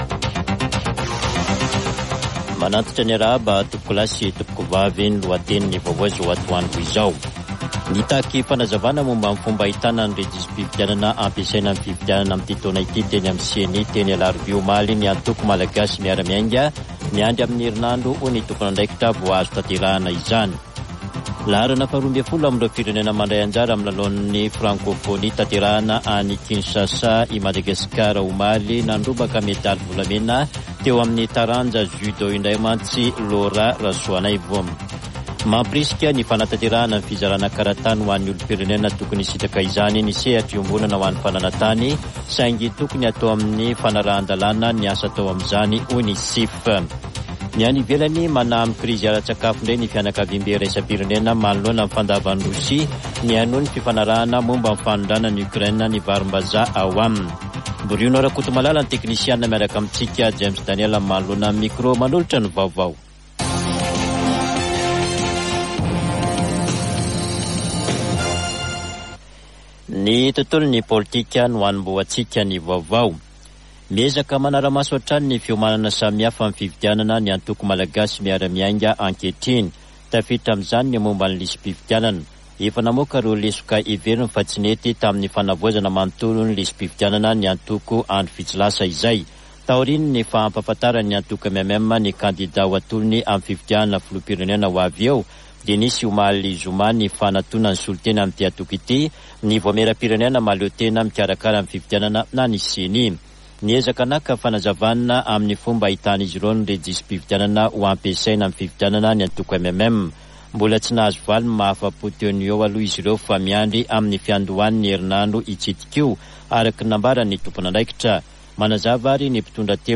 [Vaovao antoandro] Sabotsy 5 aogositra 2023